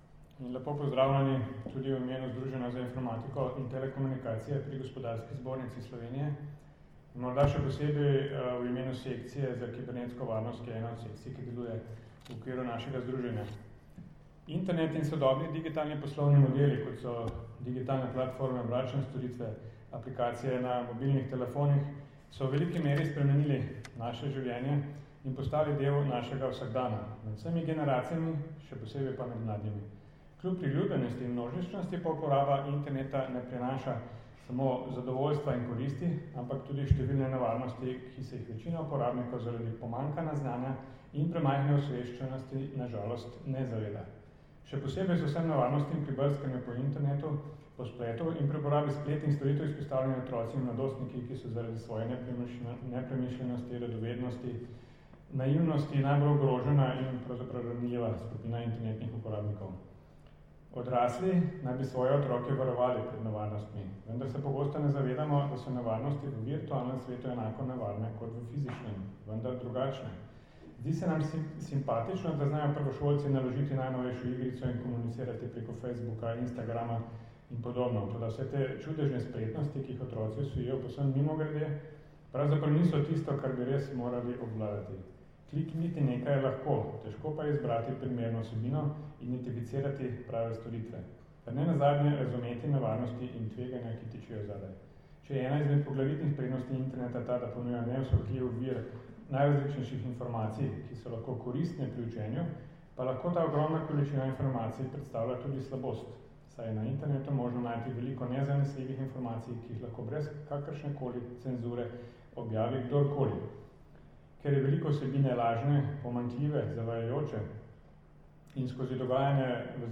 Na Fakulteti za družbene vede je včeraj, 27. septembra 2018, potekal osmi posvet na temo zlorab otrok na internetu z naslovom Obravnava e-zlorab otrok: iz teorije v prakso.
Zvočni posnetek nagovora